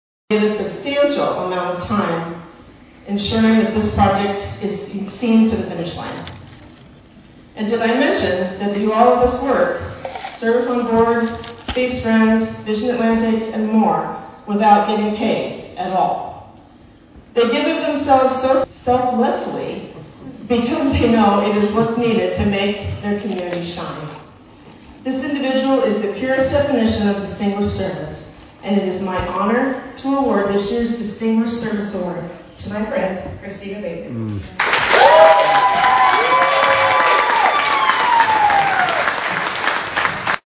(Atlantic) – Each year, the Atlantic Area Chamber of Commerce honors an outstanding community leader with its highest accolade, the Distinguished Service Award, presented at the Annual Awards Banquet on Friday evening.
The Annual Atlantic Chamber of Commerce Awards Banquet was held at The Venue in downtown Atlantic.